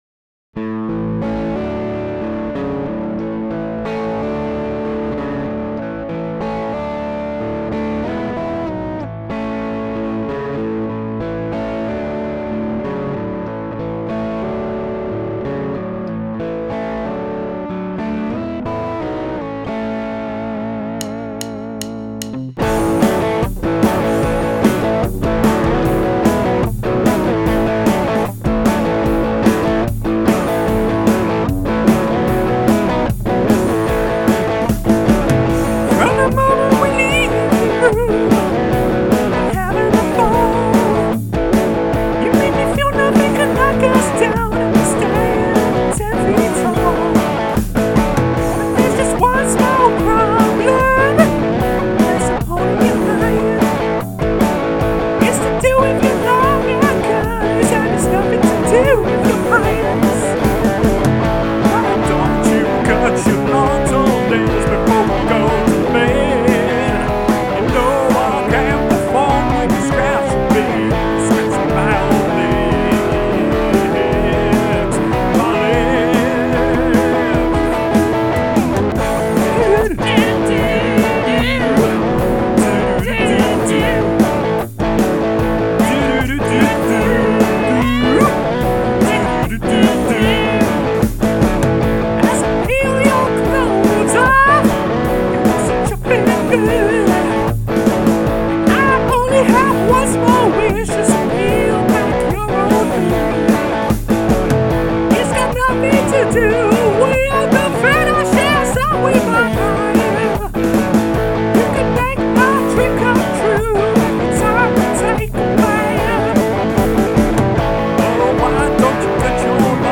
jaunty rock and roller